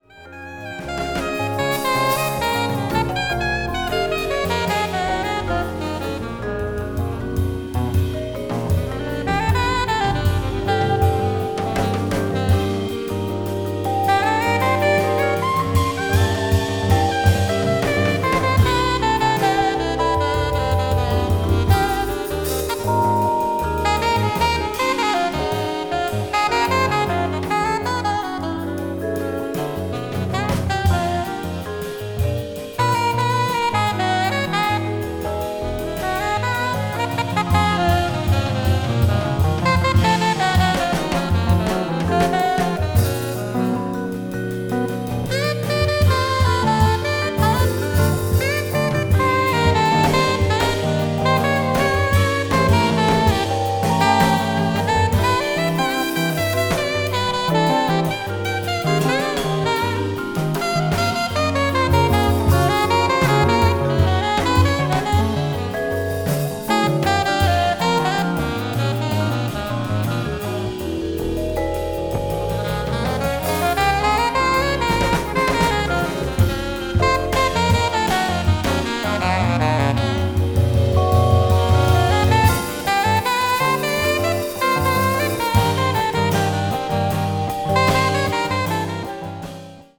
contemporary jazz